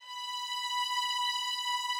Added more instrument wavs
strings_071.wav